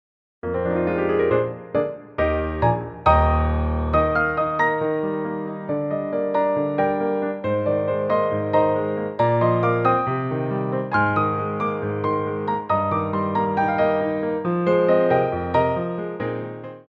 Coda
2/4 (8x8)